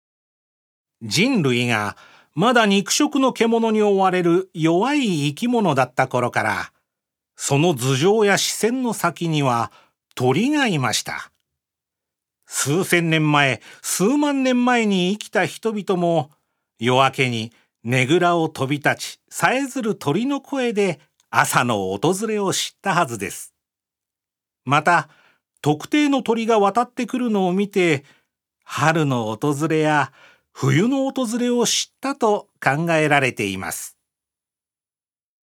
所属：男性タレント
音声サンプル
ナレーション２